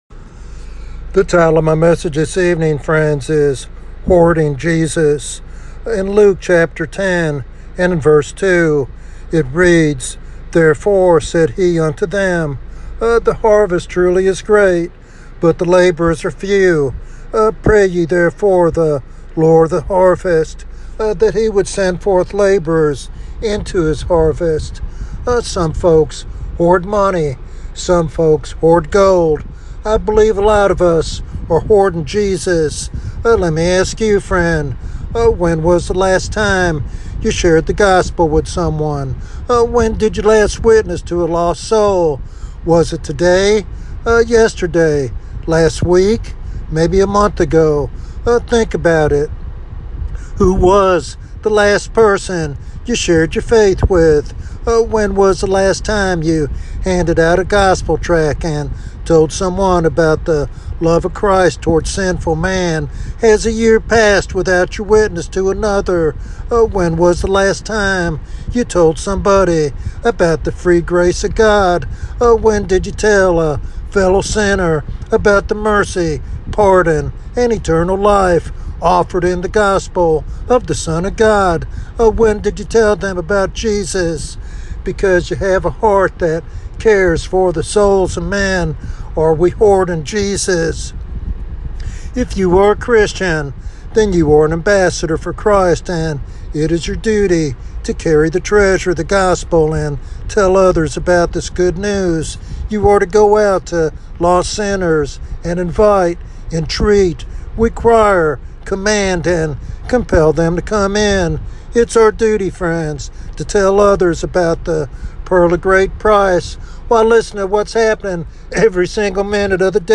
This sermon challenges Christians to embrace their role as ambassadors of Christ and to live with eternal urgency.